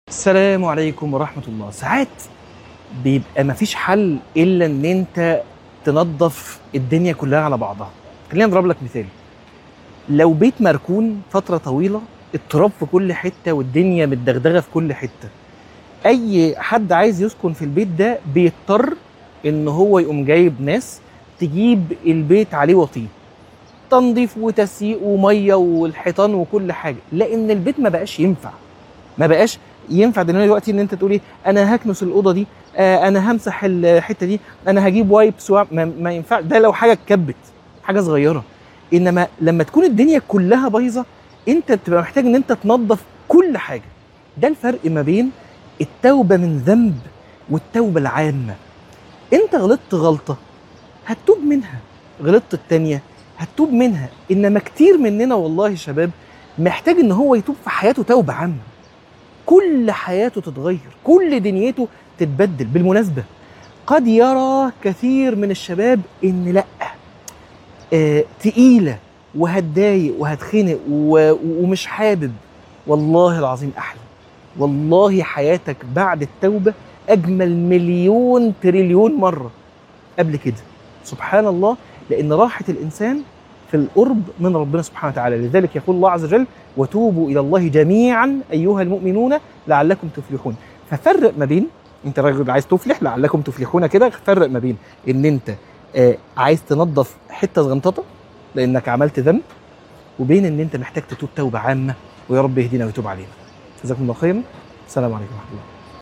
عنوان المادة نظف كل حاجة - من الحرم